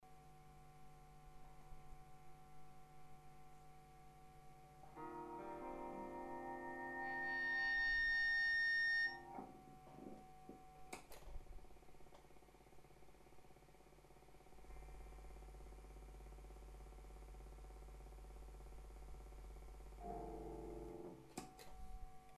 Собсно шум подавляет хорошо, но при этом добавляет звук "вертолёта".